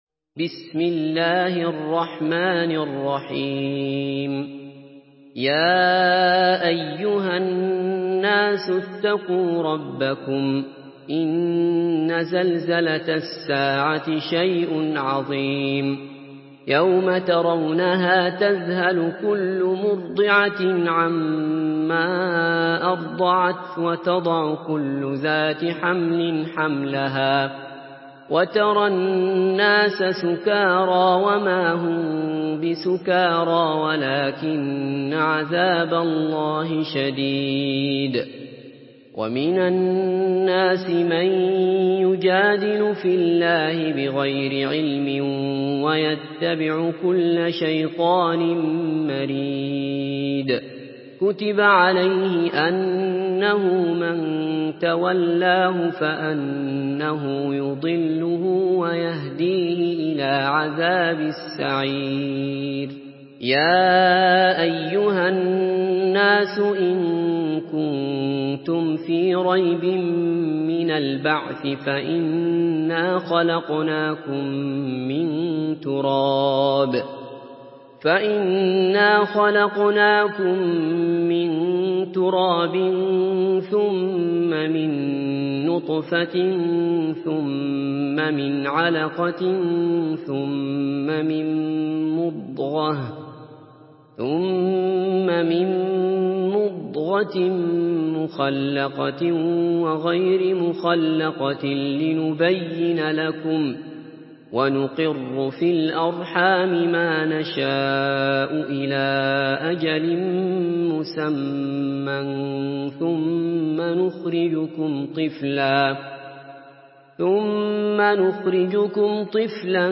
Surah Al-Haj MP3 by Abdullah Basfar in Hafs An Asim narration.
Murattal